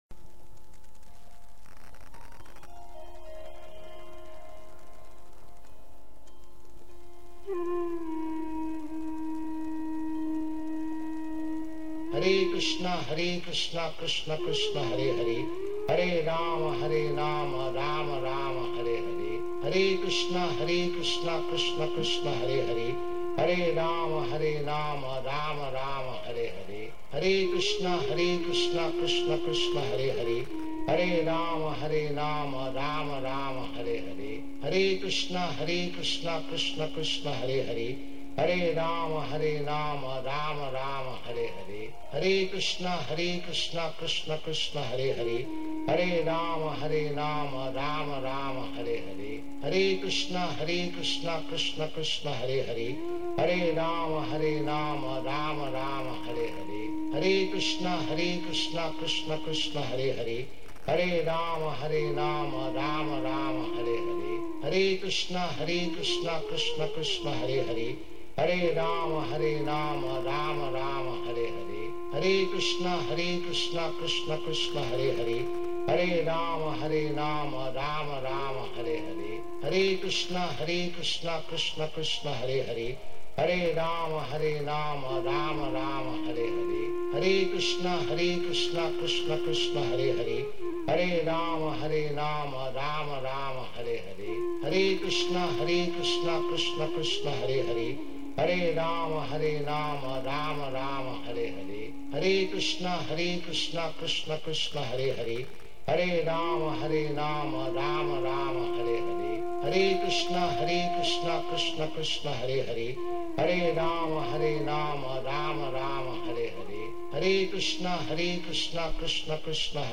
Prabhupada-chanting-maha-mantra.mp3